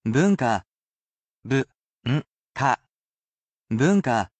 I also read the word for you aloud. I sound it out, and you can repeat after me.